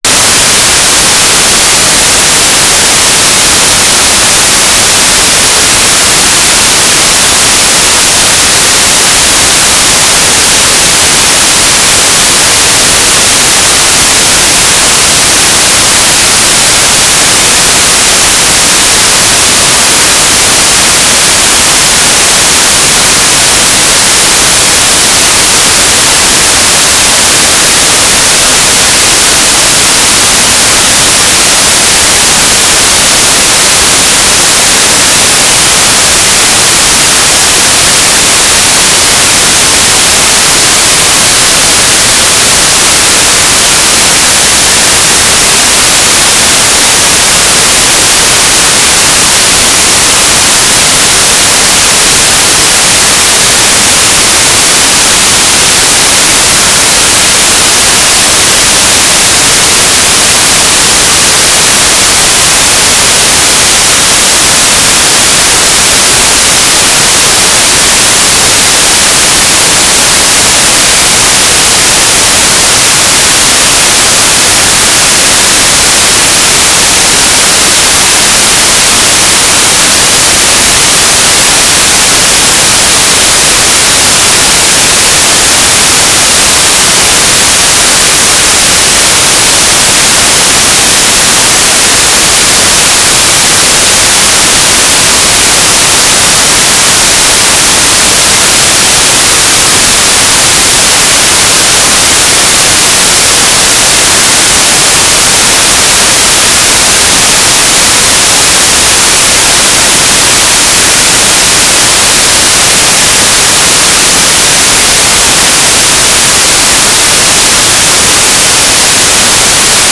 "station_name": "Quadrifilar Helix (QFH) antenna (Orlando, FL)",
"waterfall_status": "without-signal",
"transmitter_description": "LRPT IQ recording usage",
"transmitter_mode": "FSK",